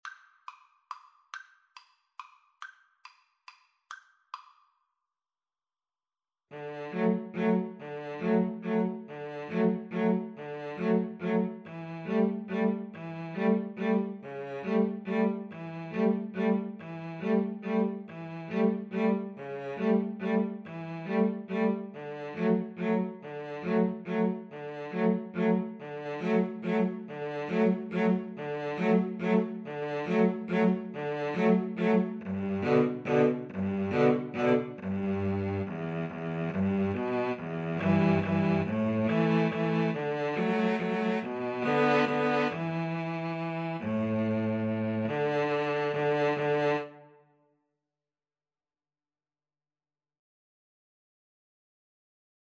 3/4 (View more 3/4 Music)
=140 Slow one in a bar